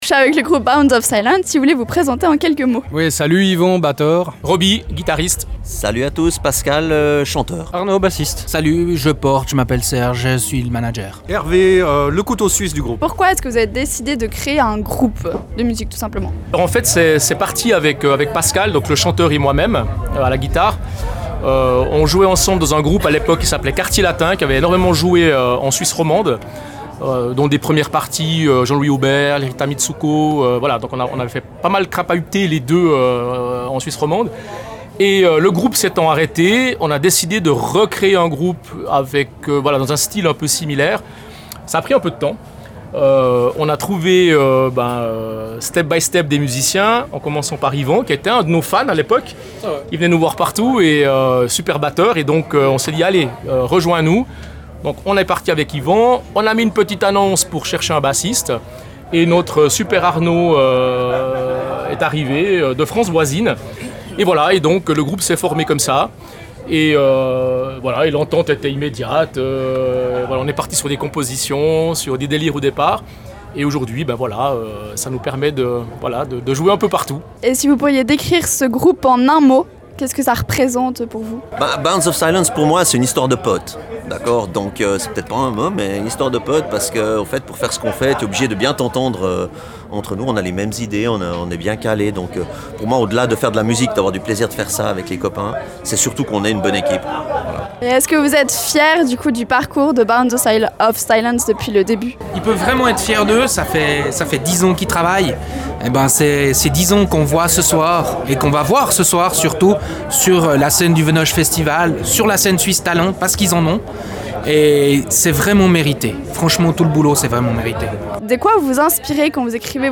Interview – Bounds Of Silence